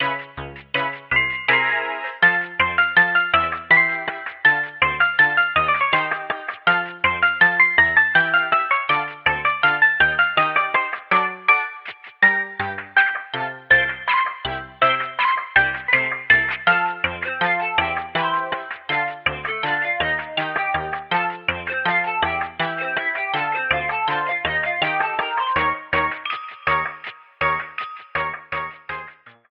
faded out the last two seconds